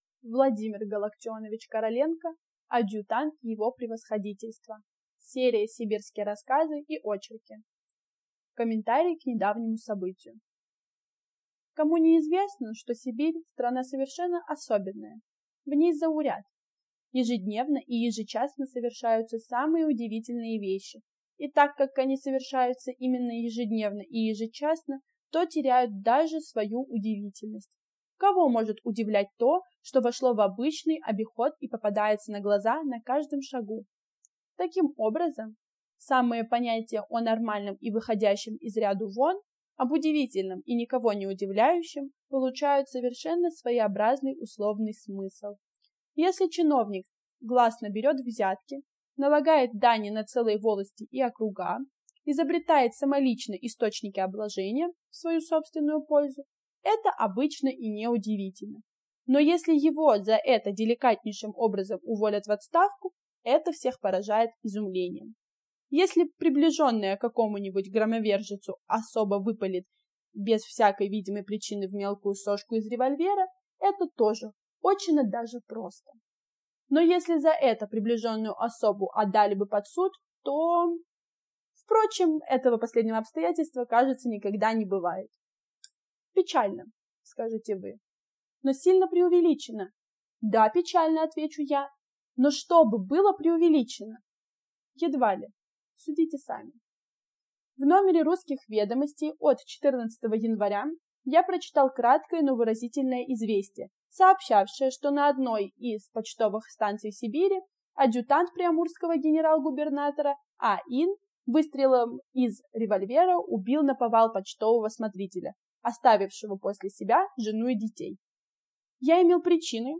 Аудиокнига Адъютант его превосходительства | Библиотека аудиокниг